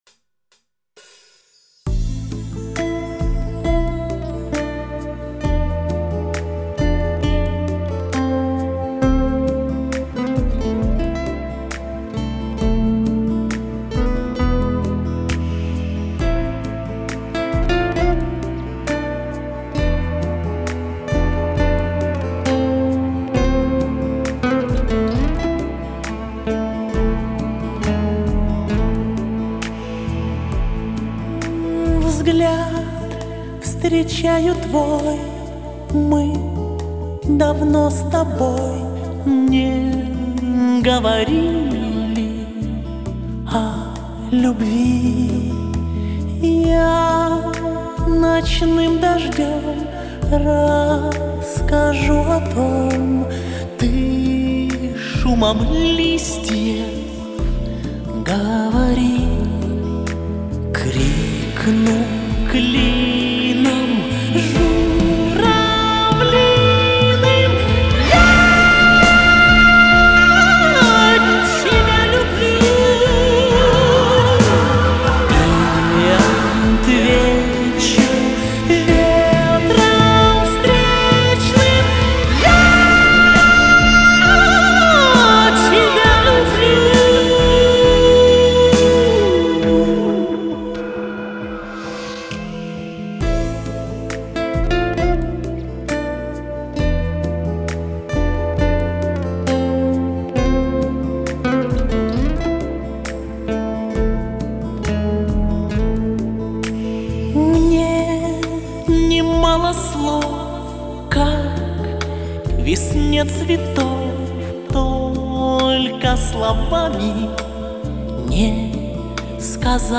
华美亮丽的声音，悦耳的旋律，